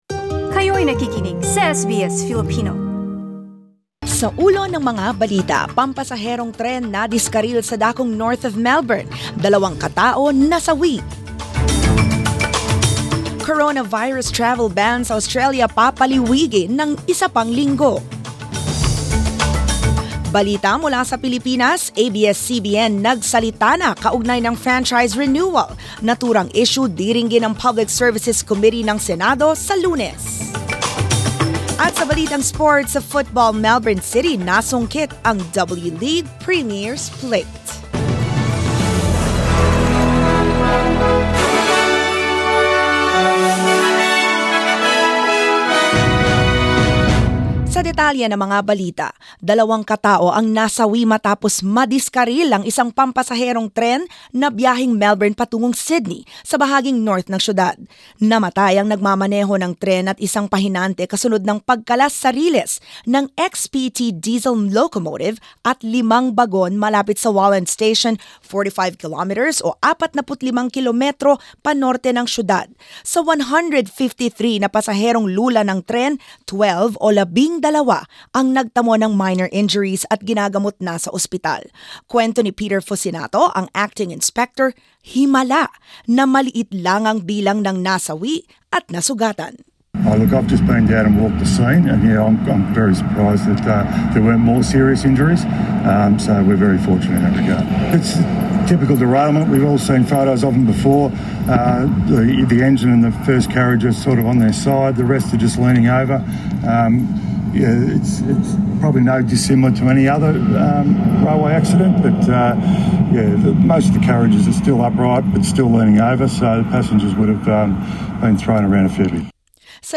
21 Feb 2020 Filipino News